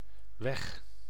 Ääntäminen
IPA : /ə.ˈweɪ/